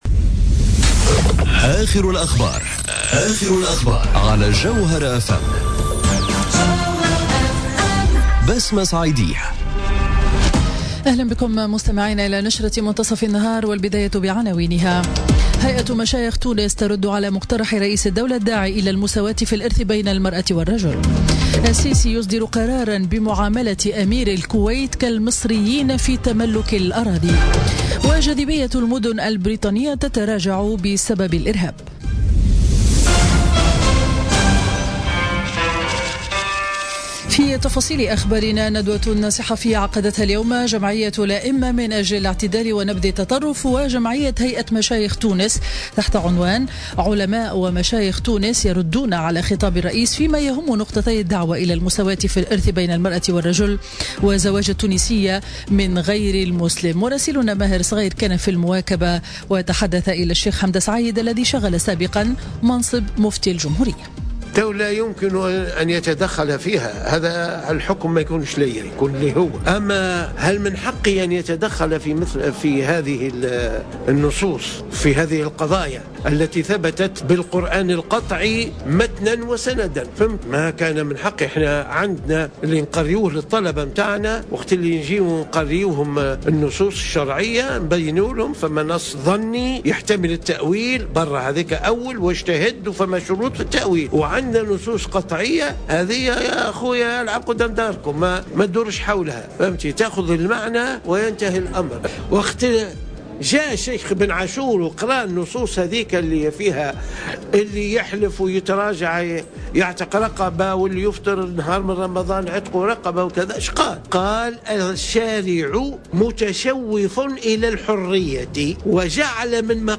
نشرة أخبار منتصف النهار ليوم الخميس 17 أوت 2017